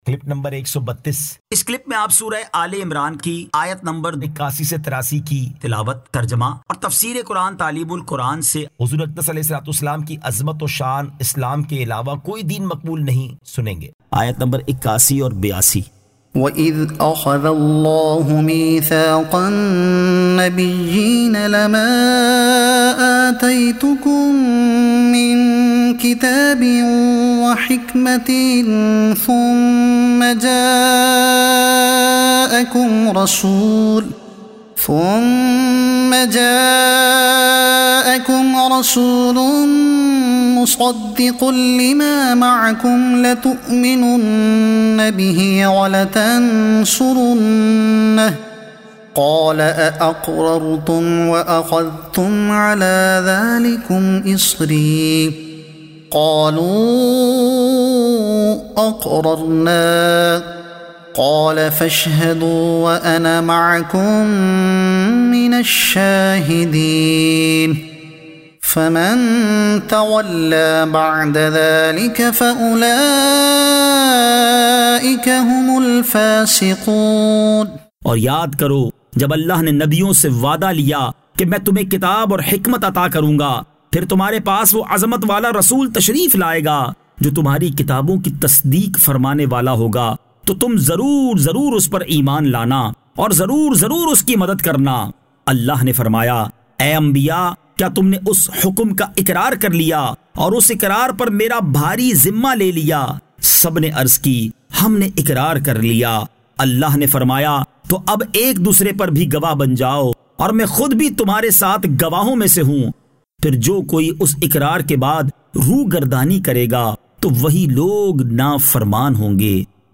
Surah Aal-e-Imran Ayat 81 To 83 Tilawat , Tarjuma , Tafseer e Taleem ul Quran